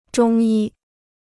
中医 (zhōng yī): traditional Chinese medical science; a doctor trained in Chinese medicine.